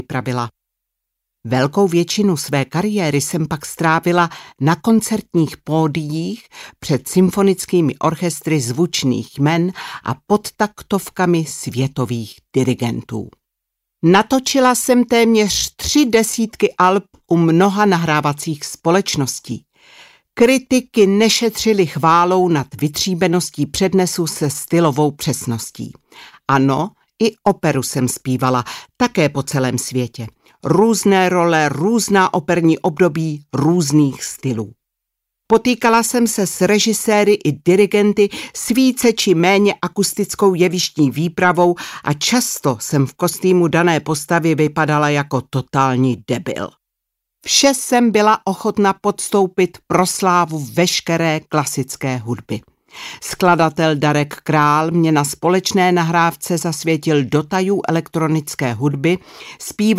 Audiobook
Read: Dagmar Pecková